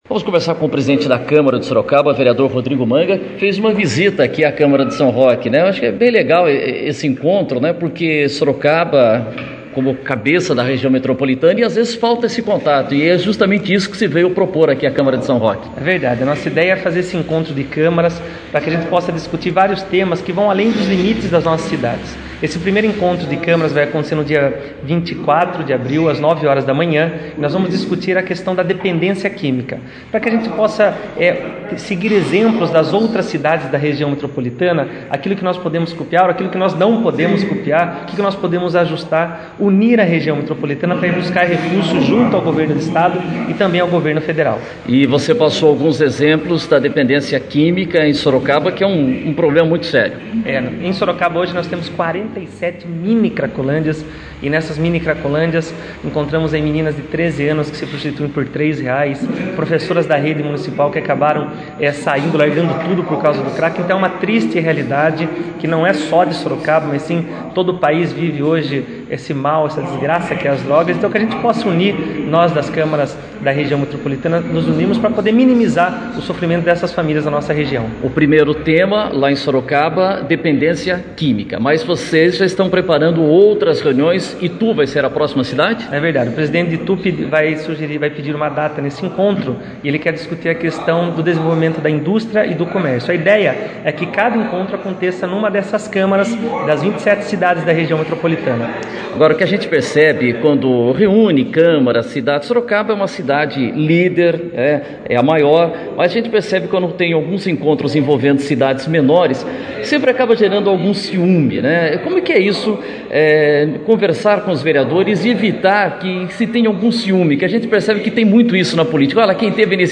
OUÇA  O PRESIDENTE DA CÂMARA DE SOROCABA RODRIGO MANGA